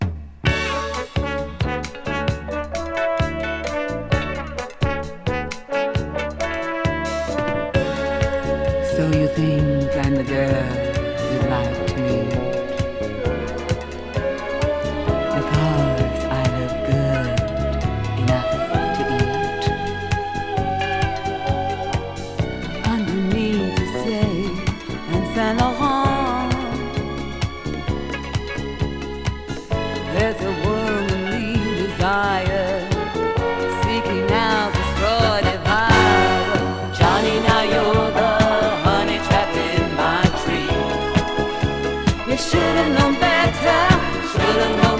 Disco, Funk, Soul　USA　12inchレコード　33rpm　Stereo